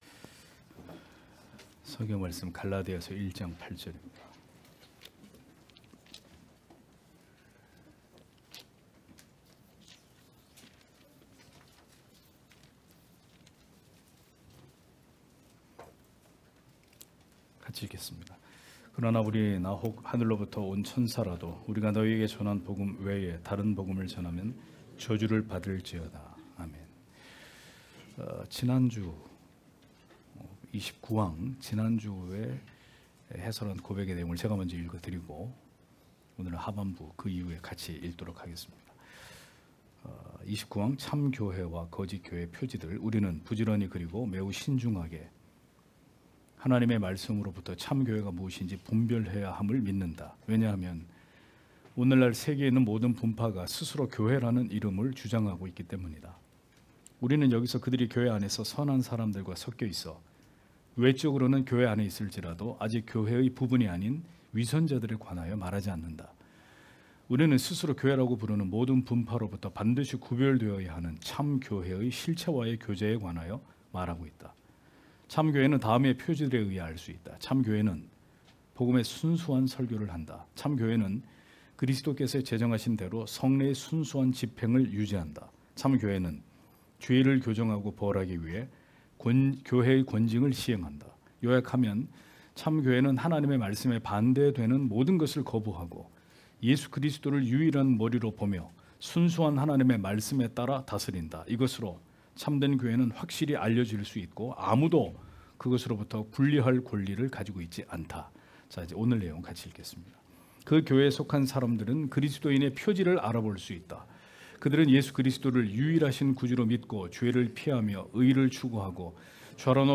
주일오후예배 - [벨직 신앙고백서 해설 34] 제29항 참 교회와 거짓 교회의 표지들(2) (갈 1장 8절)